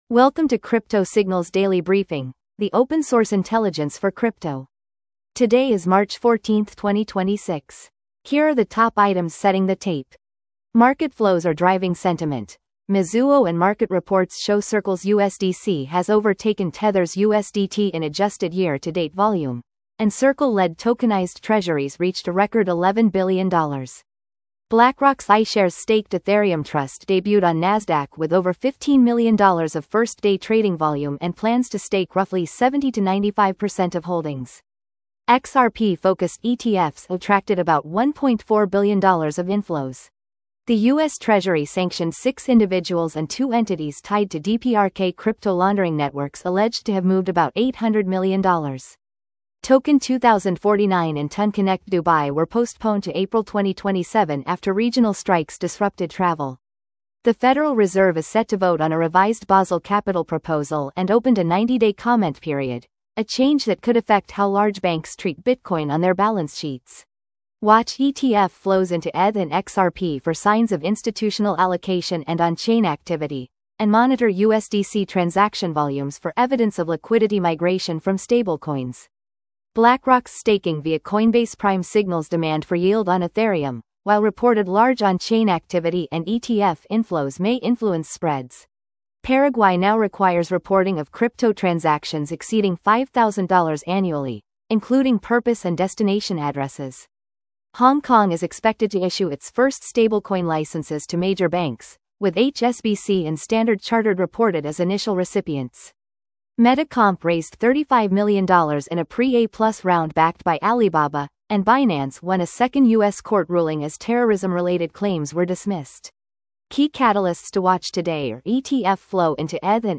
Crypto Signals Daily market brief